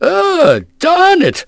Oddy-Vox_Skill5-3.wav